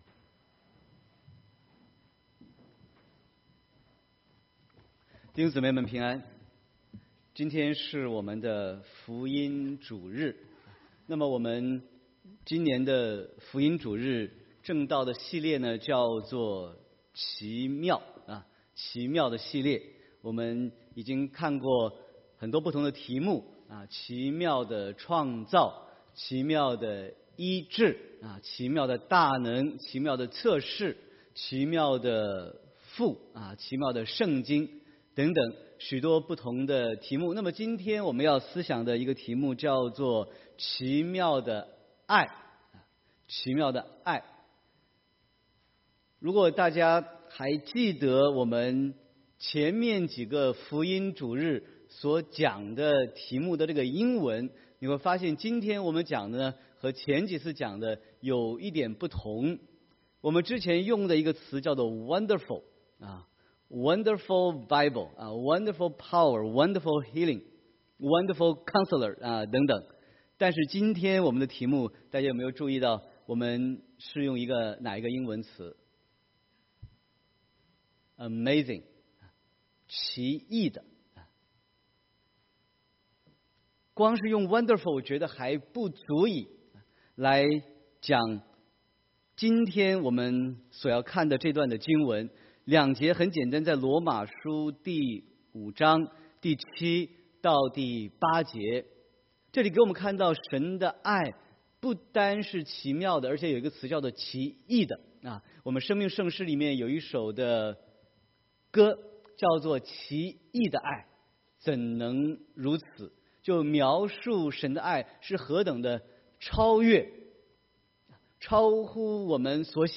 Sermon 9/16/2018